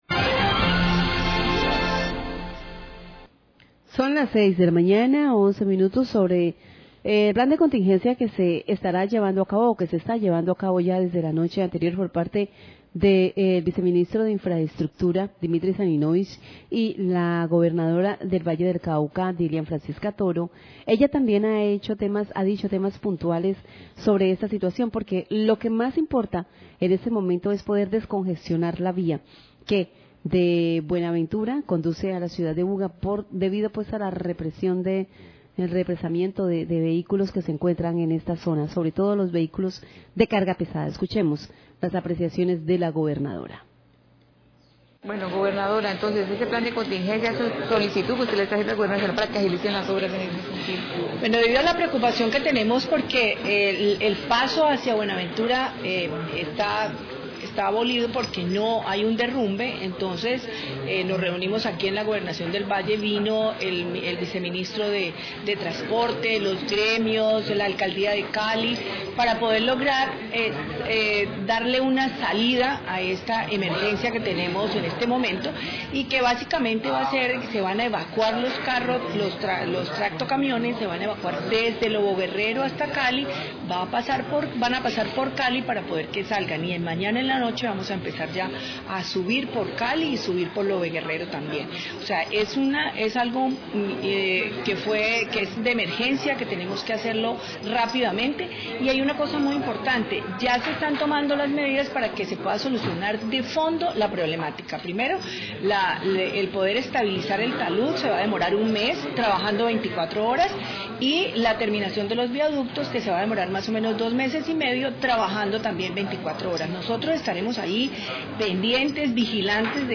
GOBERNADORA HABLÓ SOBRE LOS PLANES PARA DESCONGESTIONAR LA VÍA LOBO GUERRERO, 6.09am
Radio